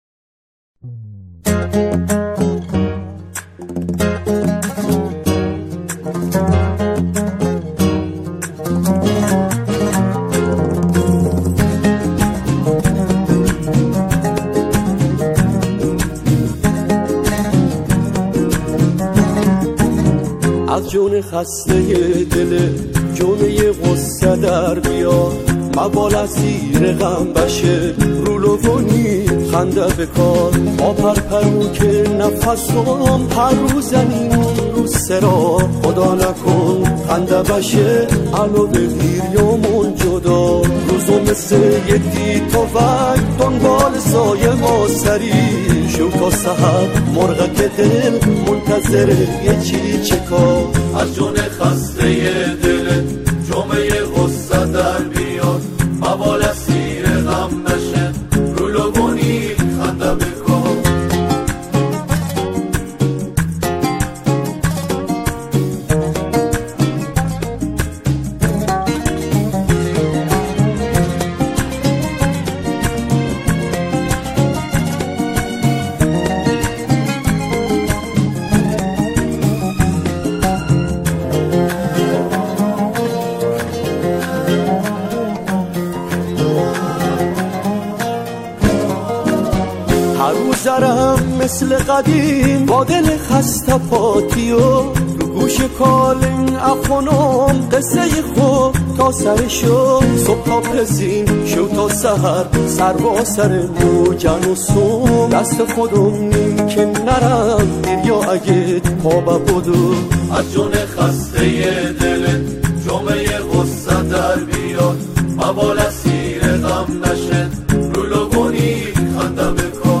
او، این قطعه را با شعری بندری اجرا می‌کند.